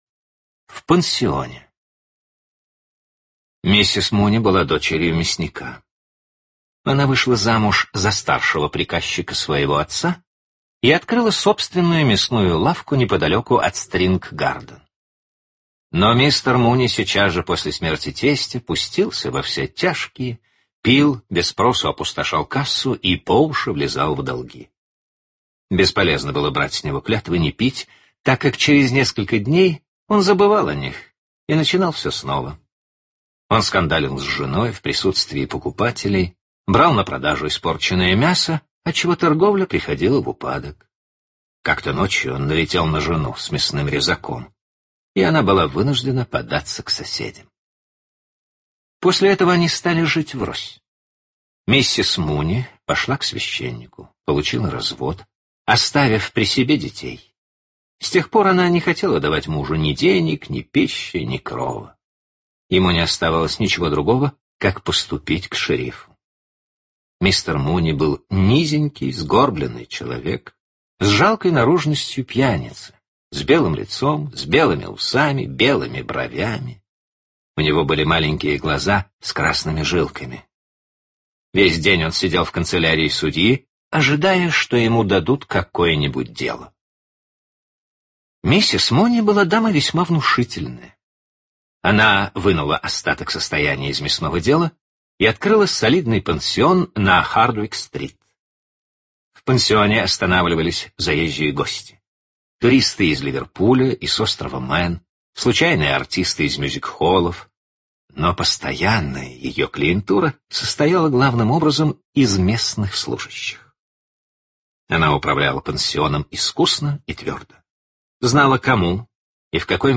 Скачать аудио книгу В пансионе Джойс, Скачать аудио книгу бесплатно В пансионе Джойс